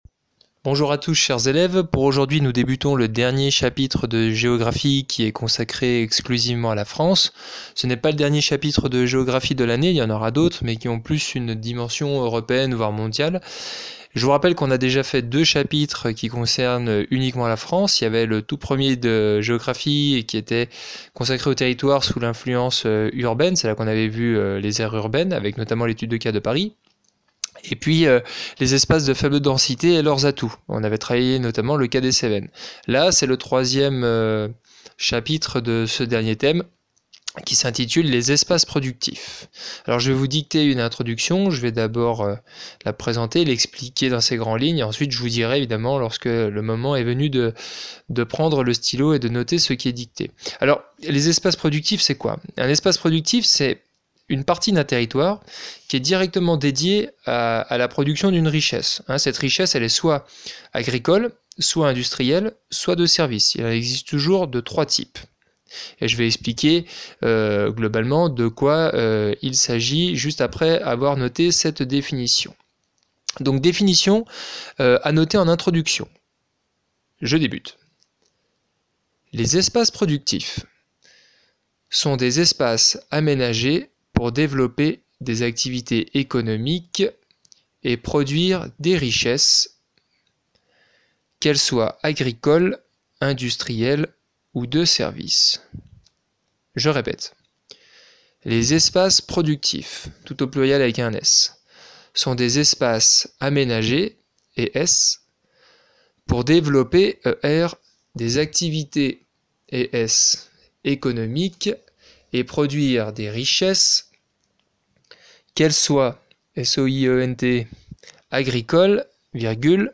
Sur le cahier, noter l'introduction dictée par le prof (